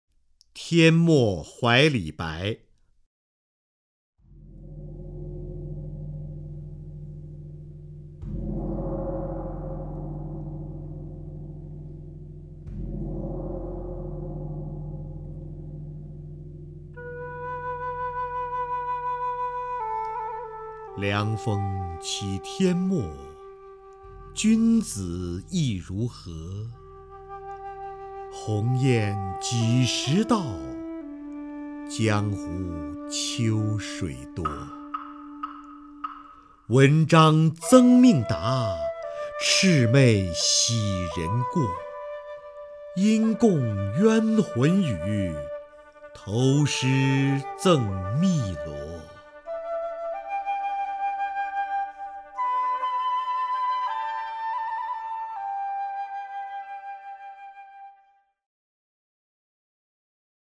首页 视听 名家朗诵欣赏 瞿弦和
瞿弦和朗诵：《天末怀李白》(（唐）杜甫)　/ （唐）杜甫
TianMoHuaiLiBai_DuFu(QuXianHe).mp3